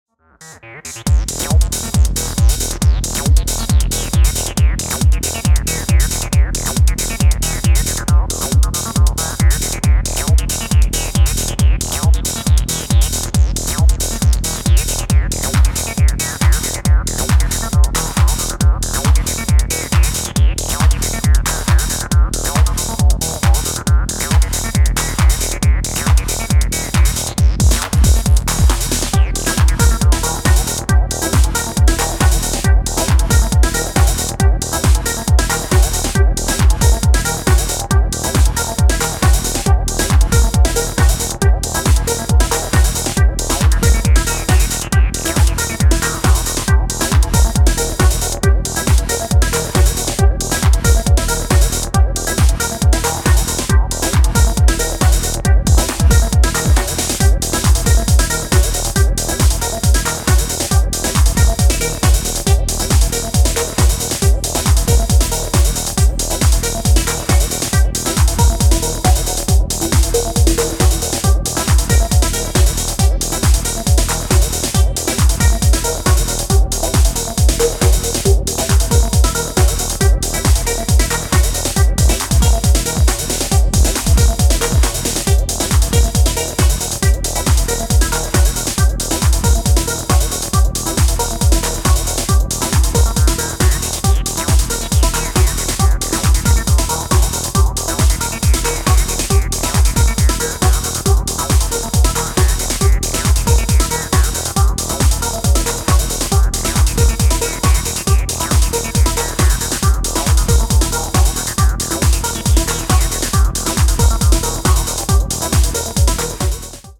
より一心不乱にアシッドトランスに邁進する